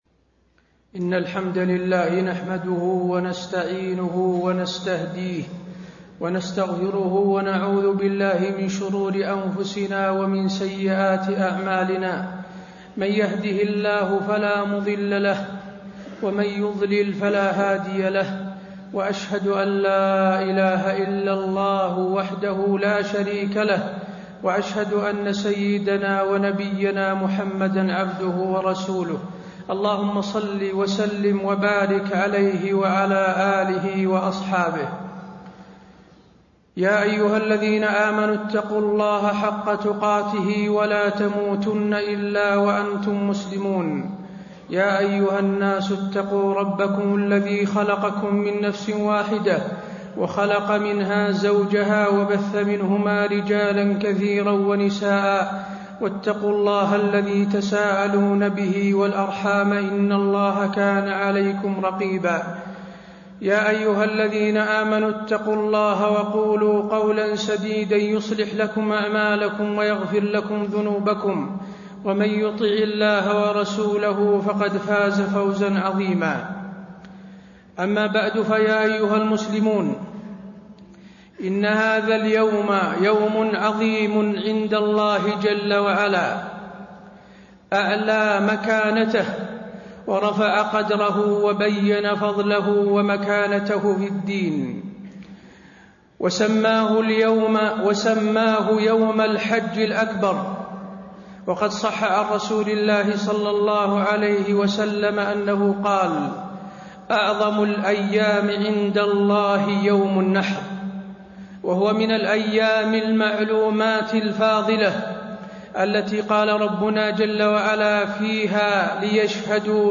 تاريخ النشر ١٠ ذو الحجة ١٤٣٣ هـ المكان: المسجد النبوي الشيخ: فضيلة الشيخ د. حسين بن عبدالعزيز آل الشيخ فضيلة الشيخ د. حسين بن عبدالعزيز آل الشيخ أيام التشريق فضائل وأحكام